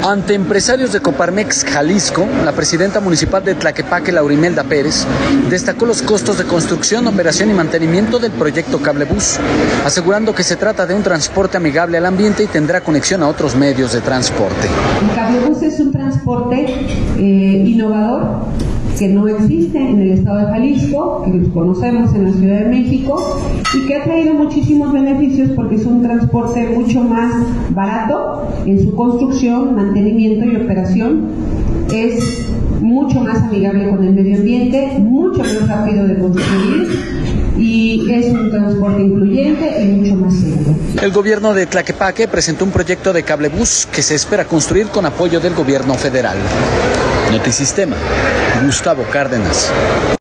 Ante empresarios de Coparmex Jalisco, la presidenta municipal de Tlaquepaque, Laura Imelda Pérez, destacó los costos de construcción, operación y mantenimiento del proyecto Cablebús, asegurando que se trata de un transporte amigable al ambiente y que tendrá conexión a otros medios de transporte.